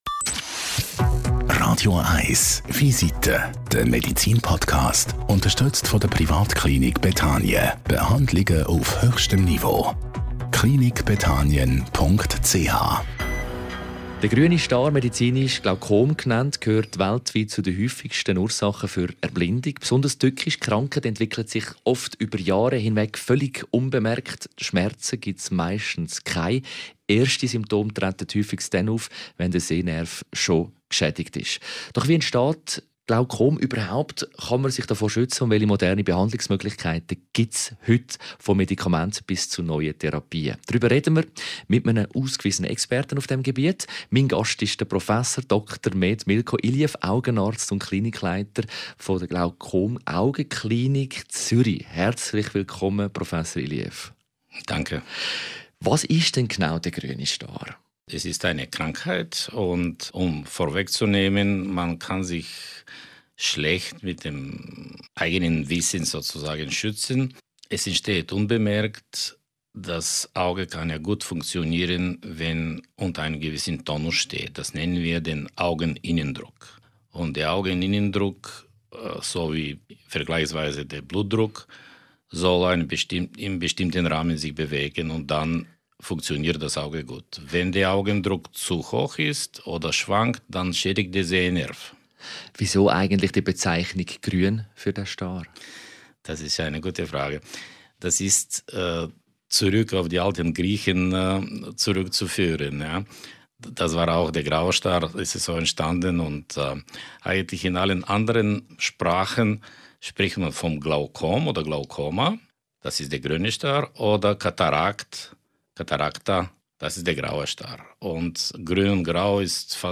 Ein Gespräch über Früherkennung, Augeninnendruck und die entscheidende Frage: Was kann jeder selbst tun, um sein Augenlicht zu schützen?